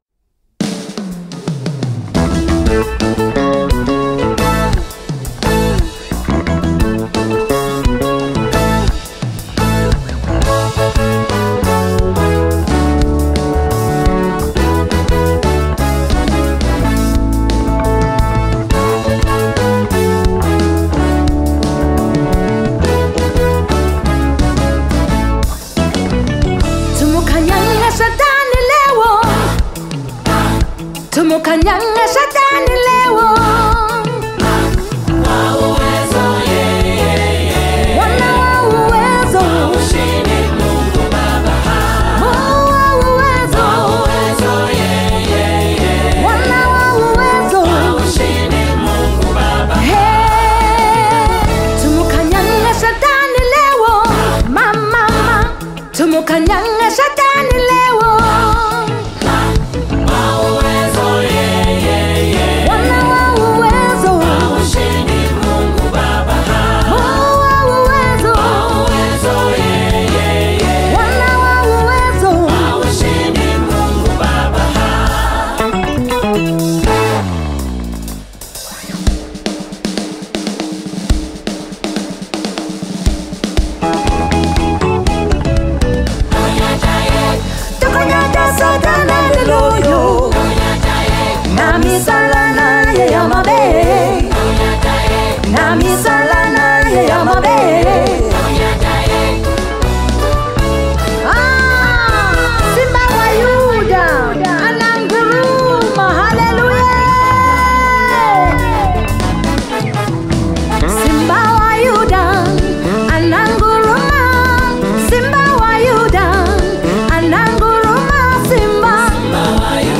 February 4, 2025 Publisher 01 Gospel 0